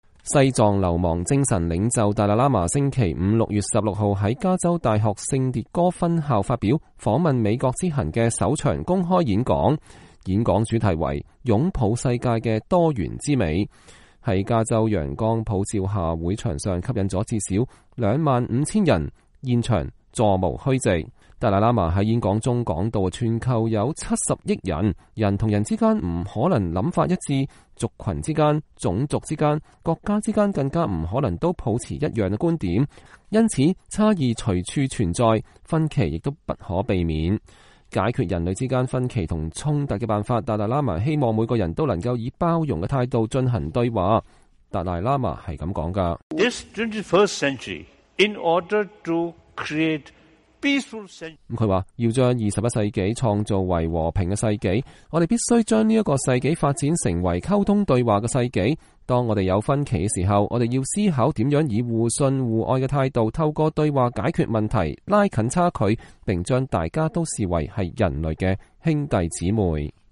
演講主題為“擁抱世界的多元之美” (Embracing the Beauty of Diversity in Our World)，在加州陽光普照下會場上吸引了至少2萬5000人，現場座無虛席。
儘管演講現場外不時聽到有人抗議的聲音，很多民眾還是贊成校長邀請達賴喇嘛的決定。